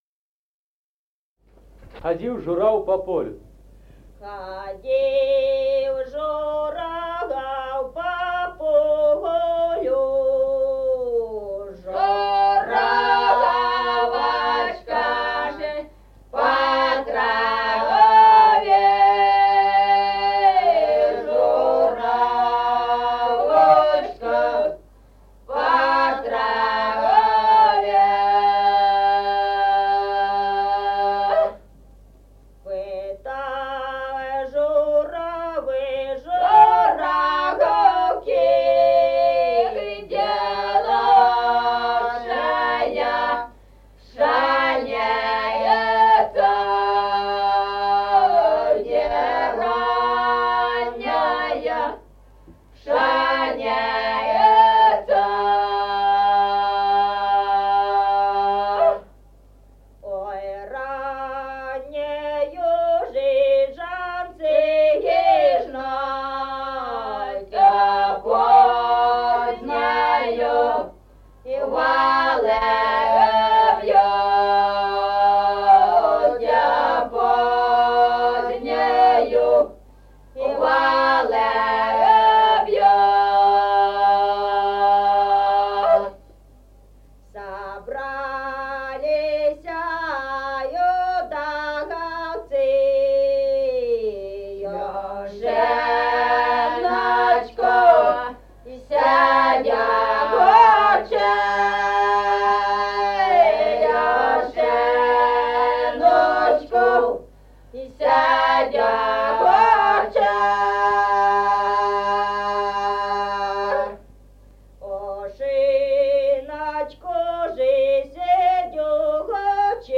Музыкальный фольклор села Мишковка «Ходил журав по полю», лирическая.